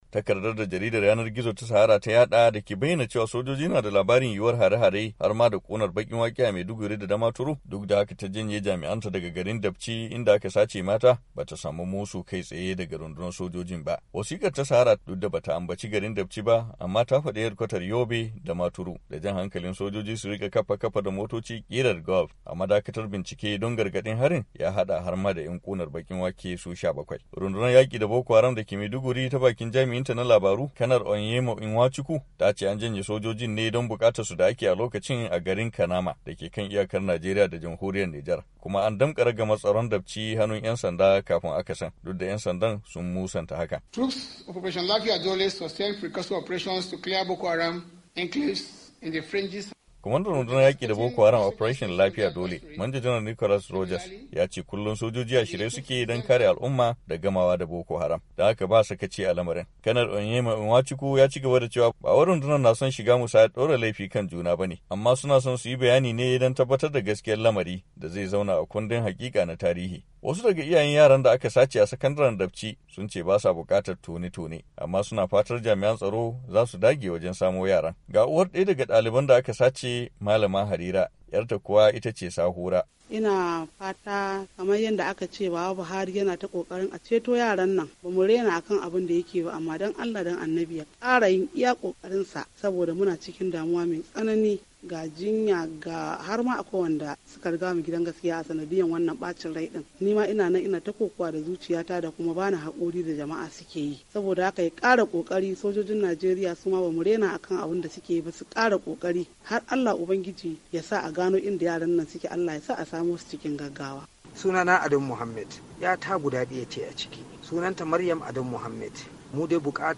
An Sanar Da Jami'an Tsaro Game Da Harin Yobe - Rahoto